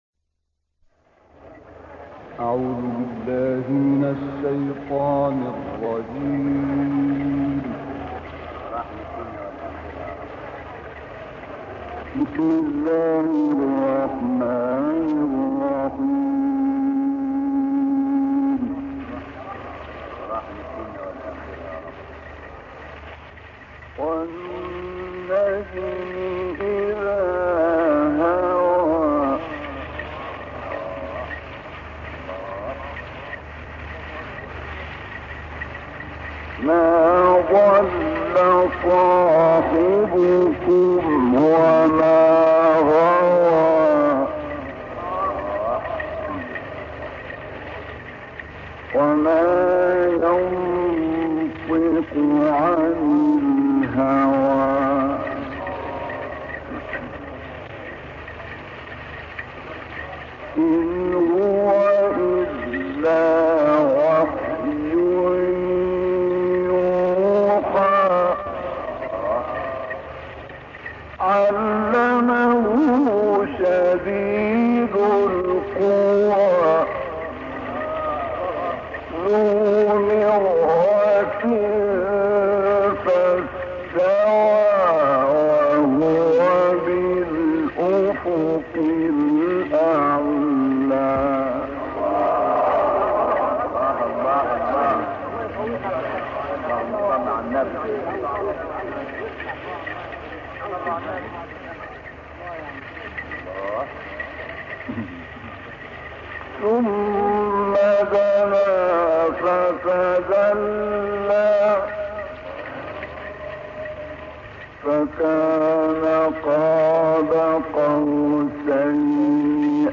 بازنشر قرائت عبدالفتاح الشعشاعی در مسجد السیده زینب + صوت
کانون خبرنگاران نبأ: عبدالفتاح الشعشاعی در قاریان پس از خود تاثیر بزرگی نهاد و با صوت و بیانی استوار از پیشگامان قرائت شد؛ کانون خبرنگاران نبأ قرائت ۳۰ دقیقه‌ای از وی در سال 1325 را بازنشر می‌دهد.